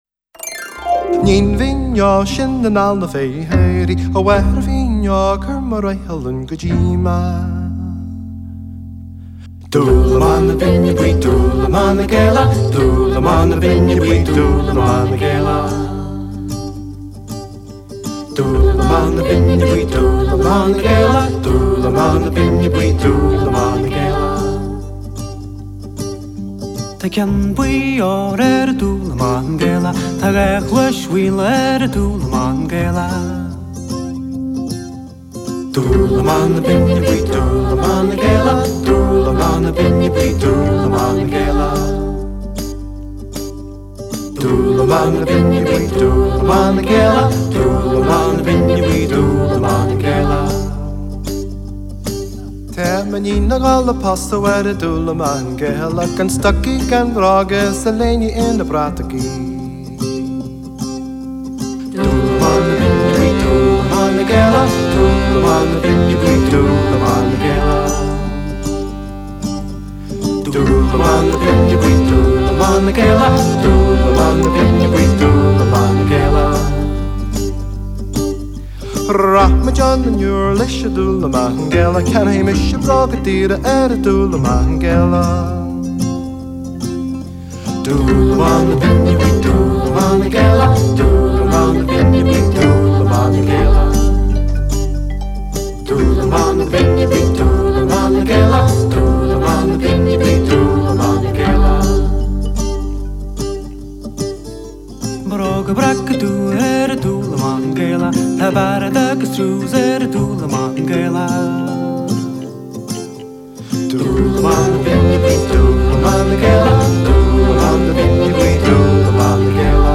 lead vocals, harmony vocals, guitar.
vocal harmonies
keyboards, piano accordion.